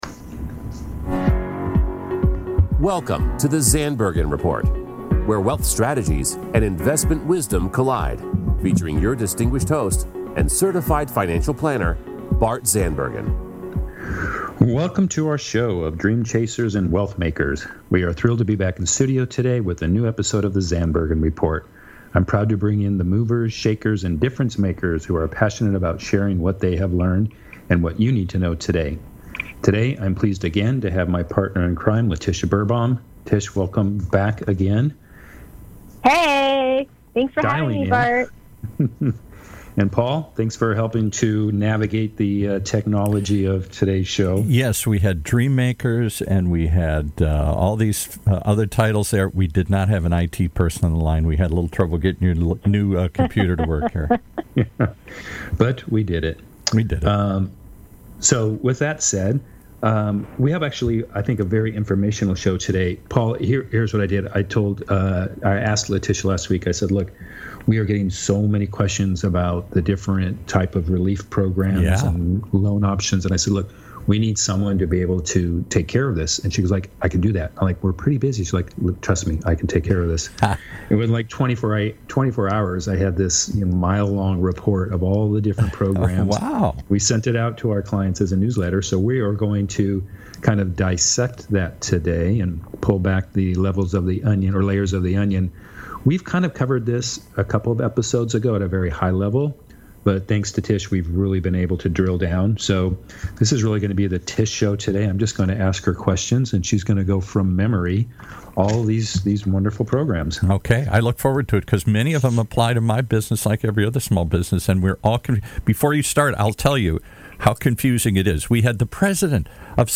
is LIVE every Tuesday at 2pm on OC Talk Radio